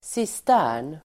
Uttal: [sist'ä:r_n]